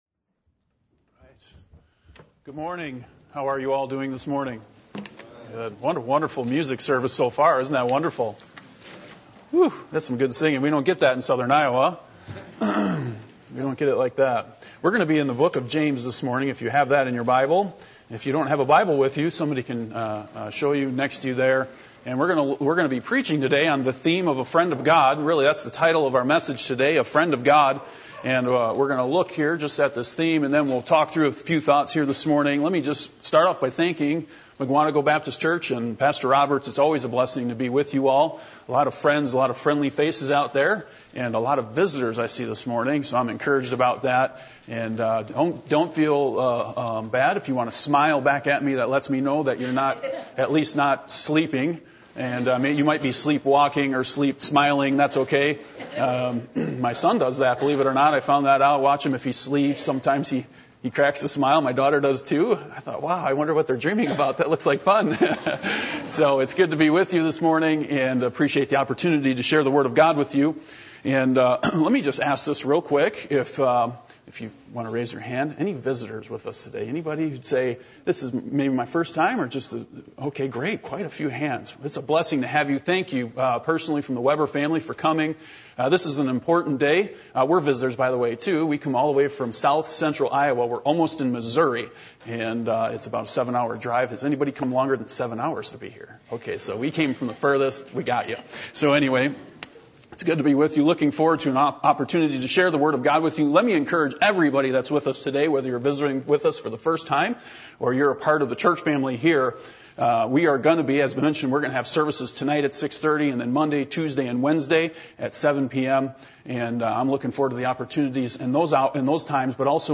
James 2:22-23 Service Type: Sunday Morning %todo_render% « Parallels Between The Human And The Divine In Revival Who Are You?